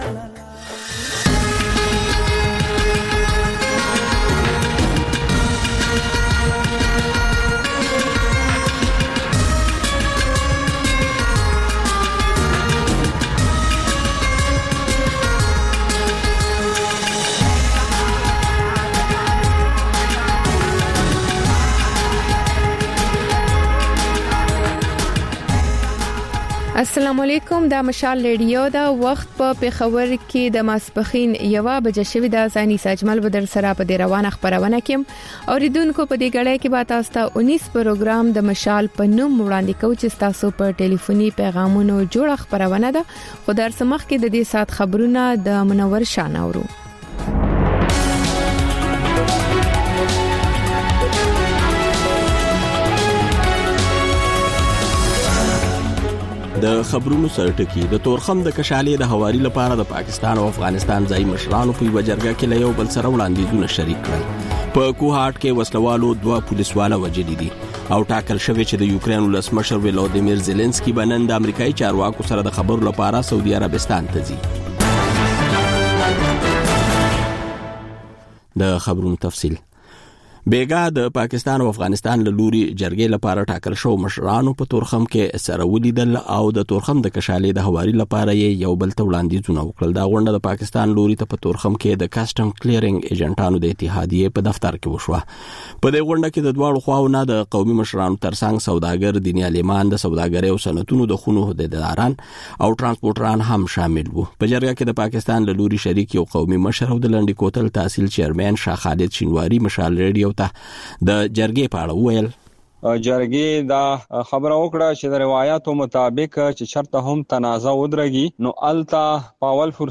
د مشال راډیو لومړۍ ماسپښينۍ خپرونه. په دې خپرونه کې تر خبرونو وروسته بېلا بېل رپورټونه، شننې، مرکې خپرېږي. ورسره اوونیزه خپرونه/خپرونې هم خپرېږي.